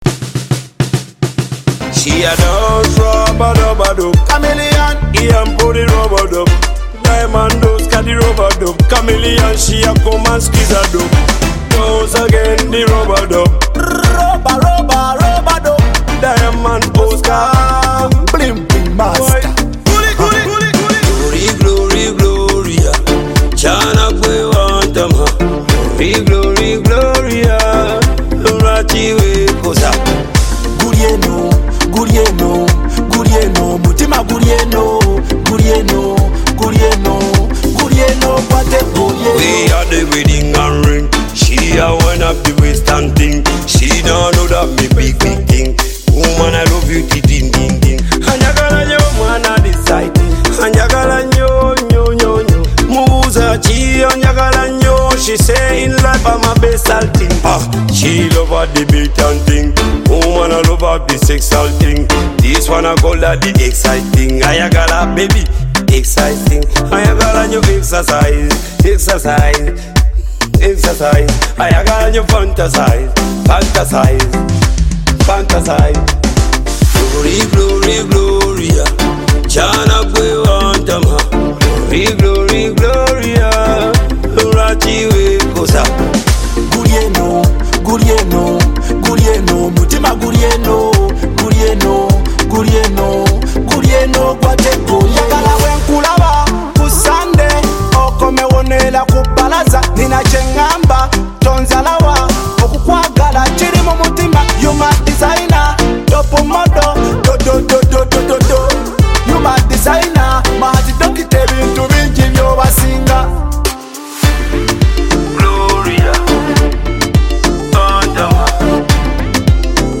vibrant